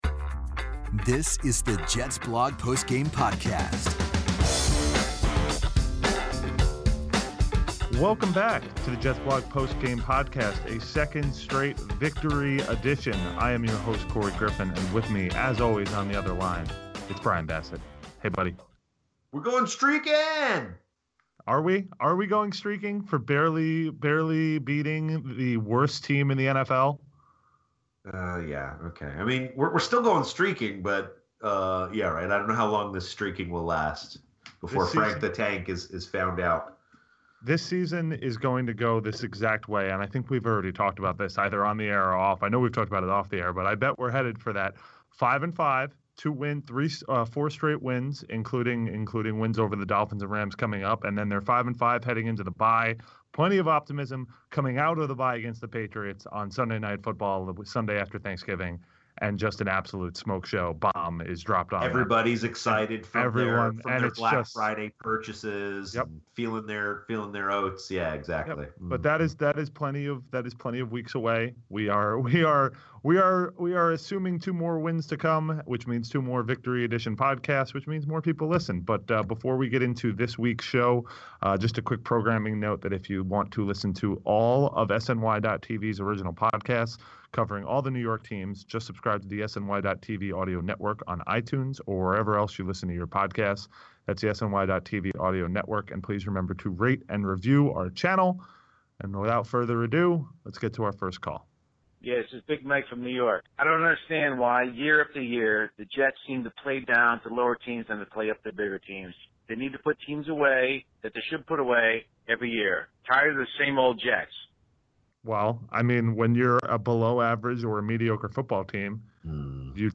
Well, sort of, with a modest 2-game winning streak for the Jets. The Post Game Podcast hears from the fans, who want to talk about an uninspiring victory, a possibly inspired Todd Bowles, and big futures for Quincy Enunwa and Bilal Powell.